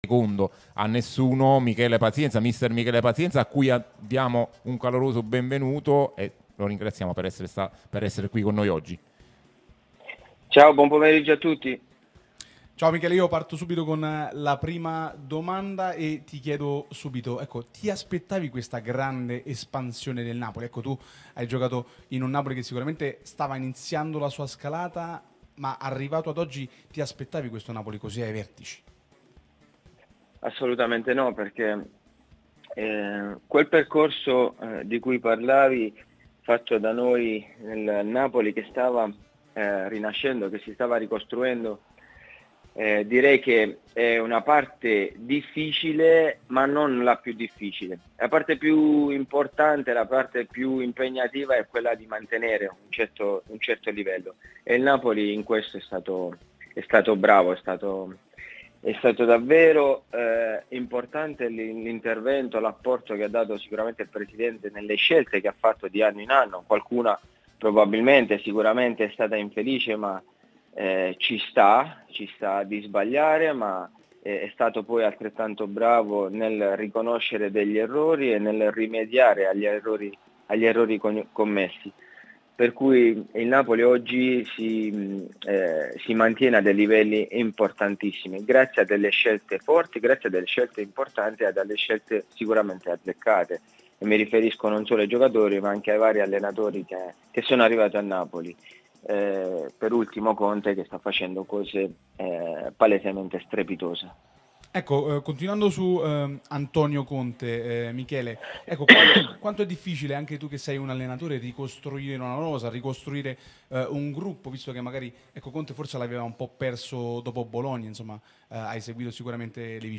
Un ascoltatore notava come Conte abbia atteggiamenti diversi a bordo campo: in alcune partite molto acceso, in altre più composto.